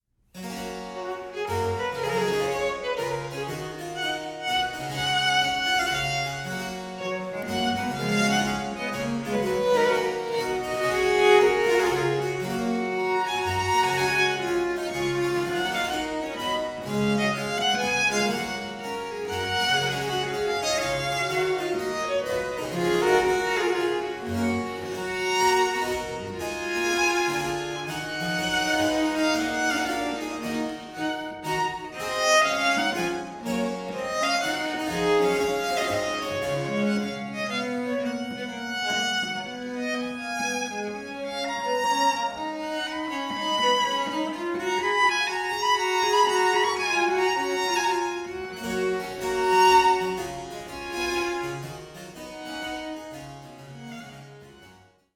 baroque violin
viola da gamba
harpsichord